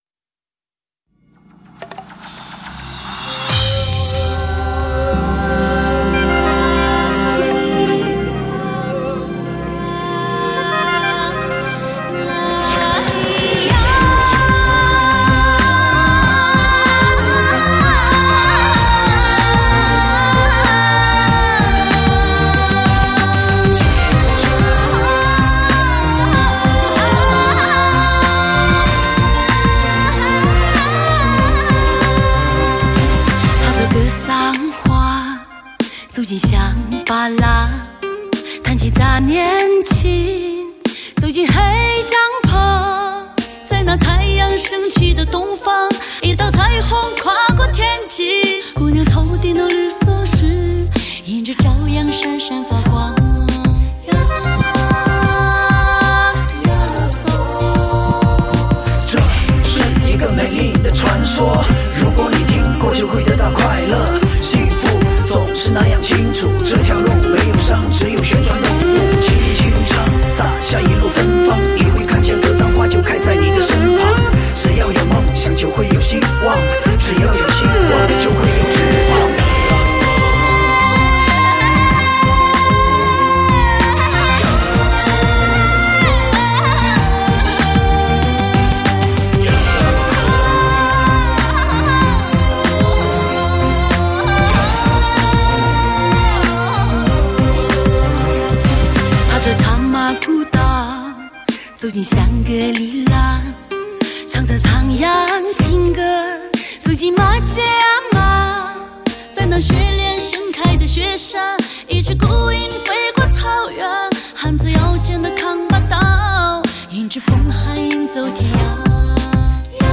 激昂中而不失温和的演绎，放歌高原的又一辉煌巨作，感受当代音乐的高亢与温柔，
聆听天地间的旷世靓音。
美妙的歌喉，高亢的说唱，仿佛在诉说一个永恒不变的古老传说……